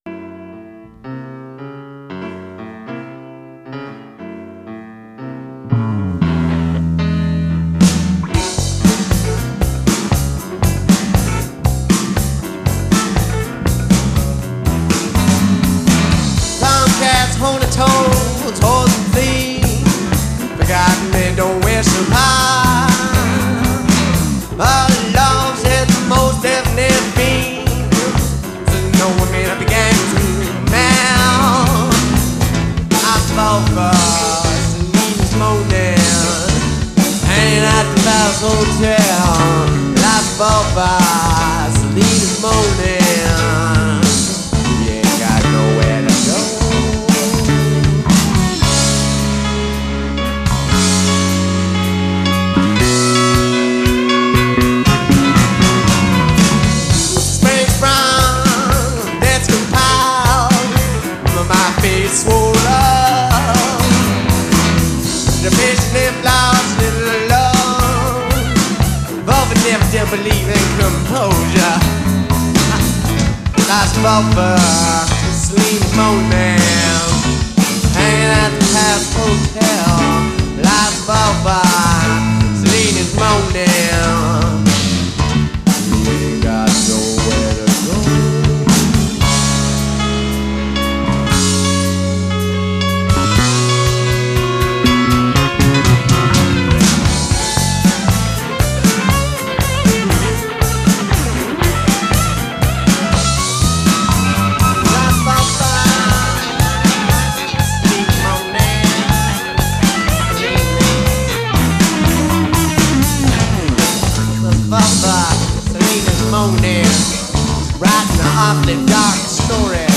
Recorded September 1987 to March 1990
Guitar, Vocals
Keyboards, Bass, Vocals